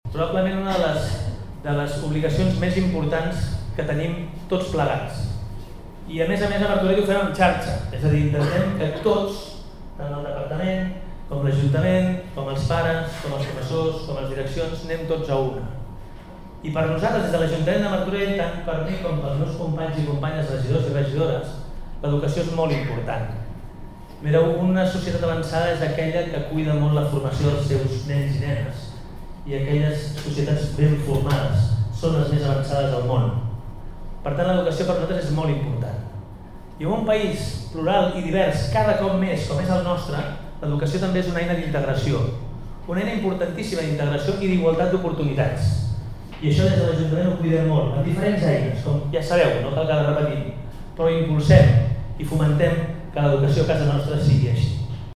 Acte inaugural del curs escolar 2025-26 a Martorell, aquesta tarda al Molí Fariner.
Xavier Fonollosa, alcalde de Martorell